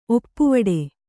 ♪ oppuvaḍe